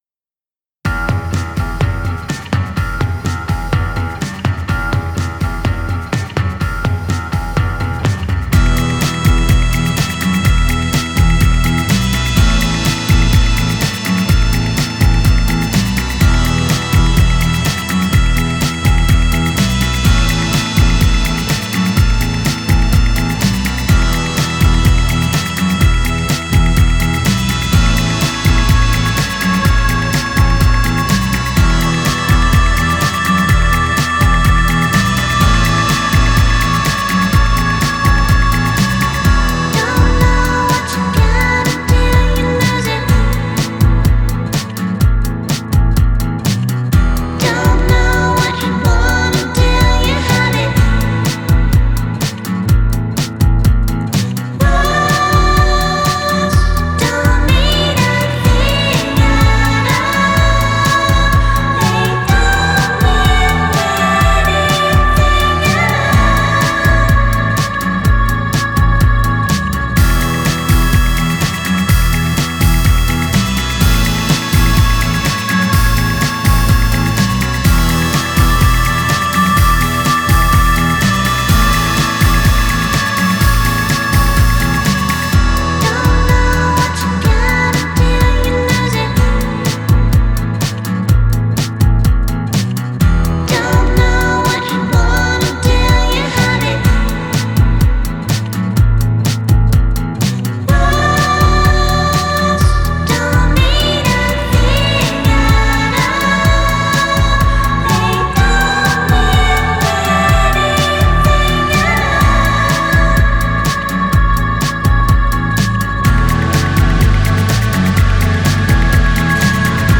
Жанр: Indie Pop
some kind of French Electro-pop